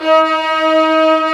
STR VIOLA03R.wav